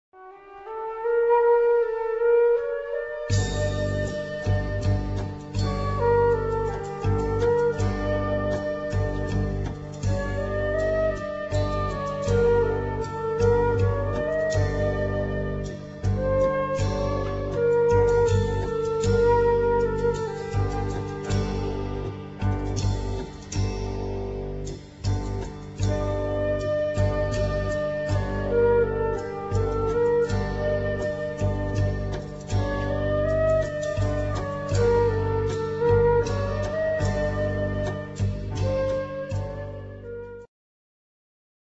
1991 sad slow instr.